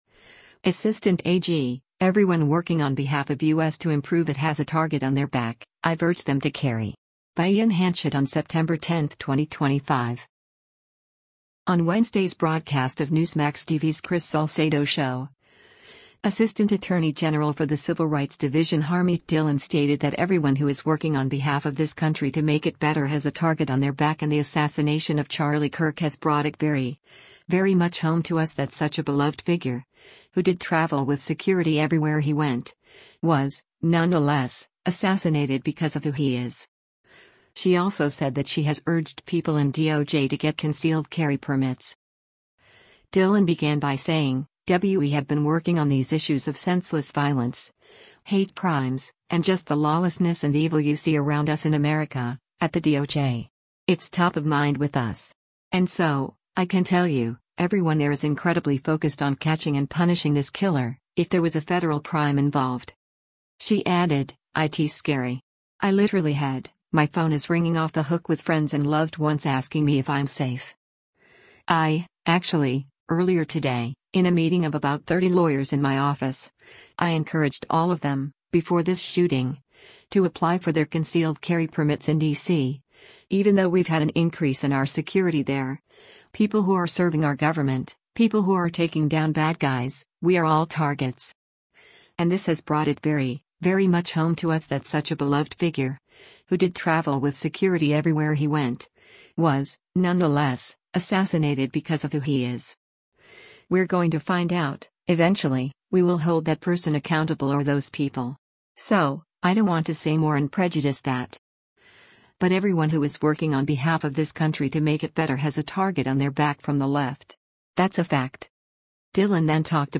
On Wednesday’s broadcast of Newsmax TV’s “Chris Salcedo Show,” Assistant Attorney General for the Civil Rights Division Harmeet Dhillon stated that “everyone who is working on behalf of this country to make it better has a target on their back” and the assassination of Charlie Kirk “has brought it very, very much home to us that such a beloved figure, who did travel with security everywhere he went, was, nonetheless, assassinated because of who he is.” She also said that she has urged people in DOJ to get concealed carry permits.